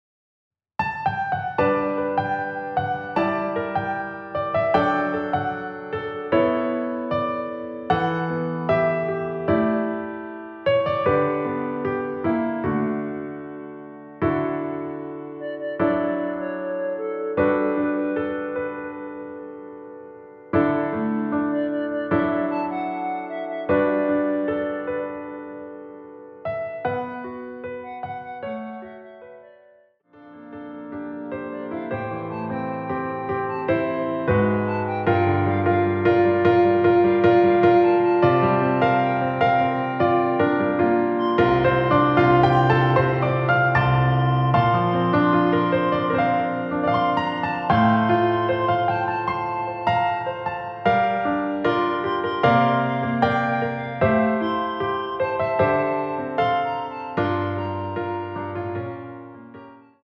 원키에서(+6)올린 멜로디 포함된 MR 입니다.(미리듣기 참조)
앞부분30초, 뒷부분30초씩 편집해서 올려 드리고 있습니다.
중간에 음이 끈어지고 다시 나오는 이유는